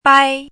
chinese-voice - 汉字语音库
bai1.mp3